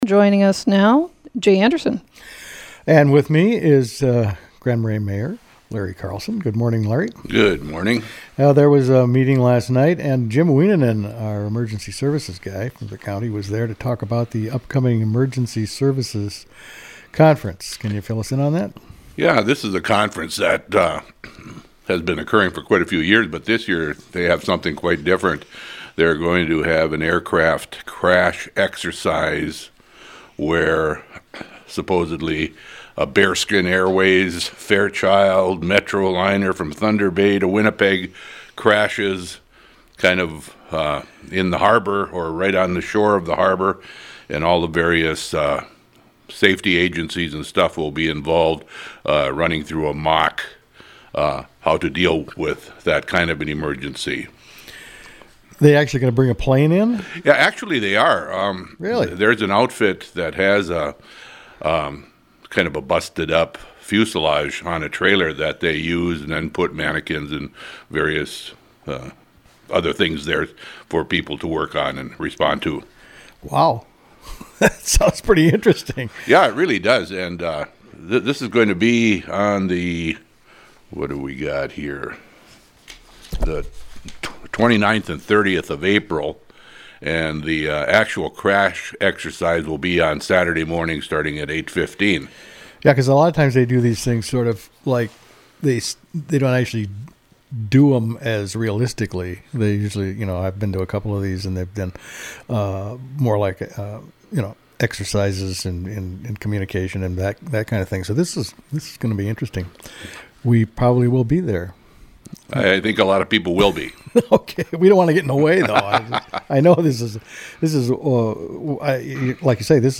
Grand Marais Mayor Larry Carlson stopped into Thursday's AM Community Calendar.